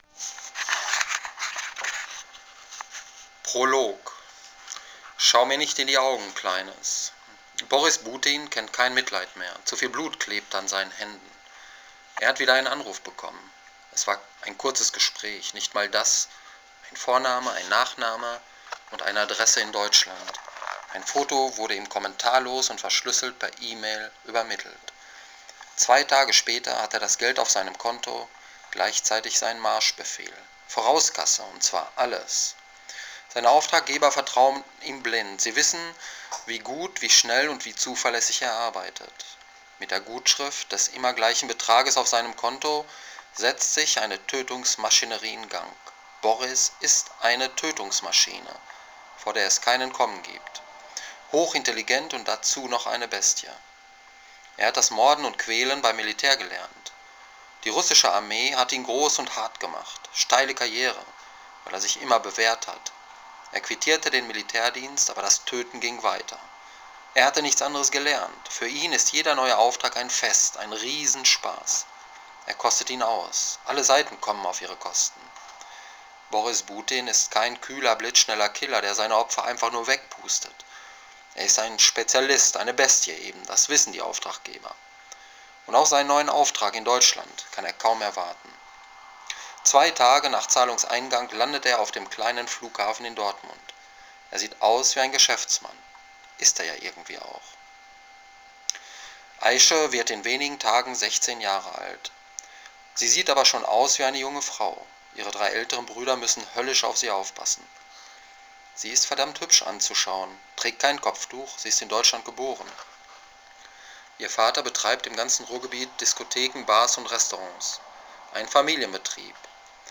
Ich dachte, liest Deinen treuen Homepagegästen mal was vor.